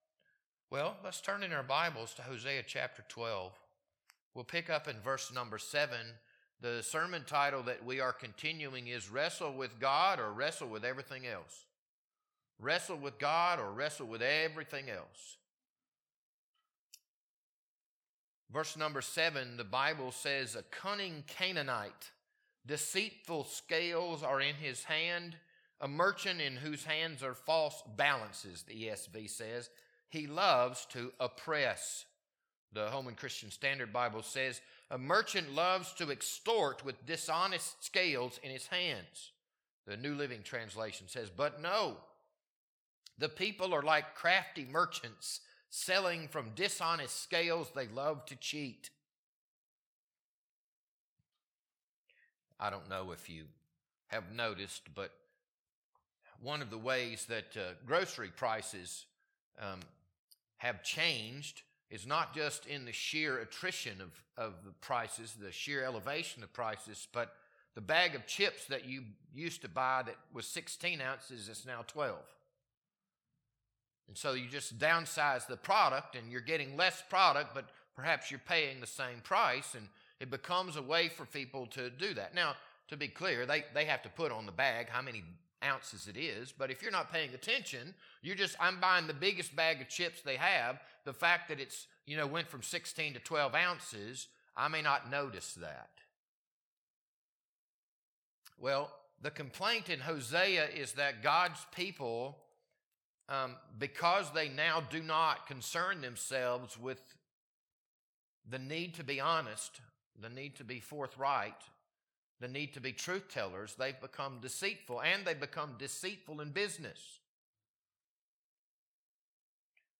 This Sunday evening sermon was recorded on February 15th, 2026.